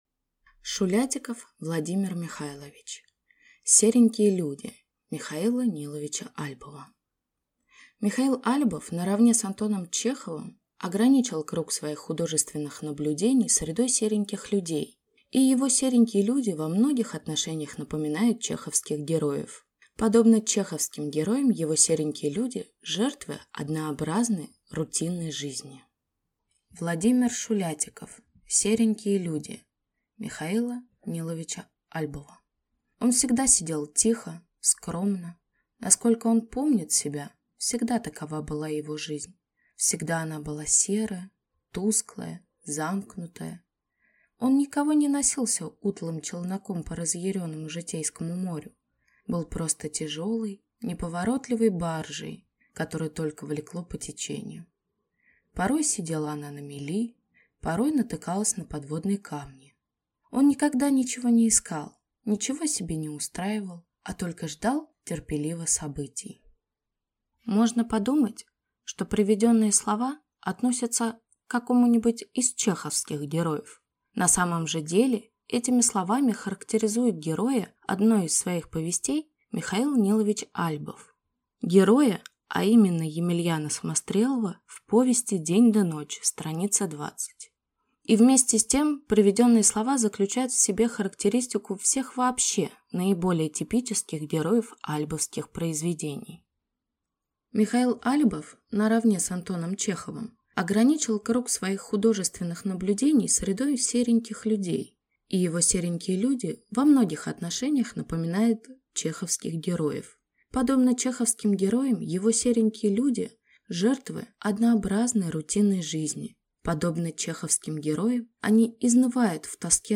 Аудиокнига Серенькие люди (М. Н. Альбов) | Библиотека аудиокниг